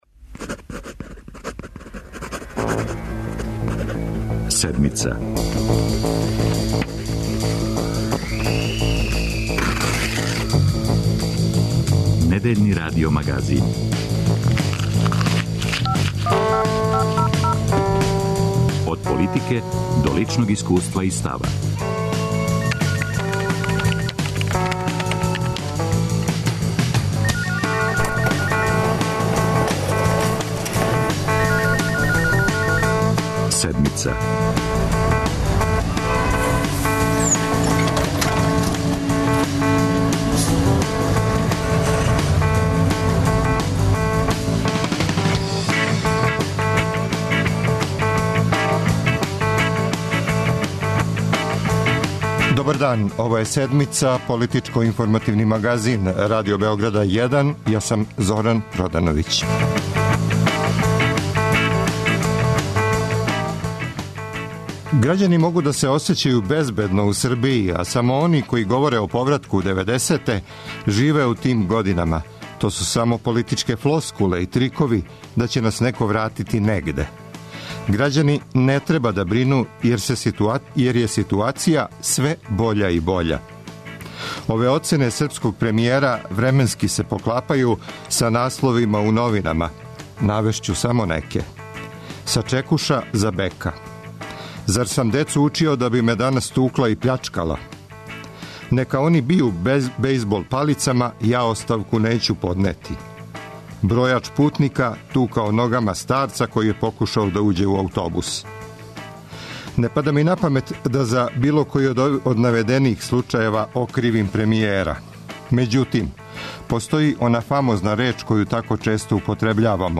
Да ли нам се 90-те привиђају или догађају? За 'Седмицу' говоре социолози,криминолози, новинари и писци.